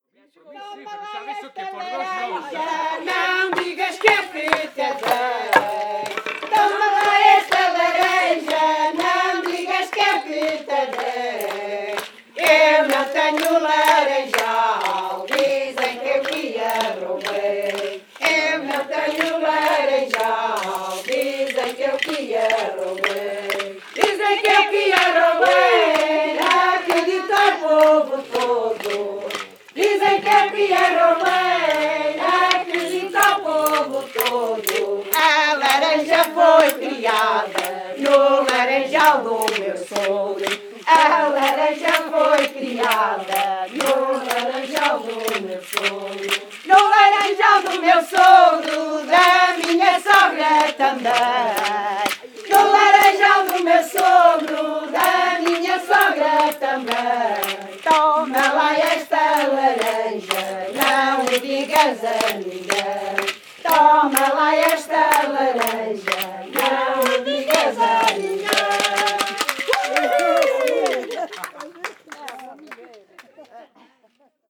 Grupo Etnográfico de Trajes e Cantares do Linho de Várzea de Calde
Calde a 22 Setembro 2016.